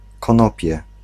Ääntäminen
Synonyymit cannabis Ääntäminen France: IPA: [ʃɑ̃vʁ] Haettu sana löytyi näillä lähdekielillä: ranska Käännös Ääninäyte Substantiivit 1. konopie Suku: m .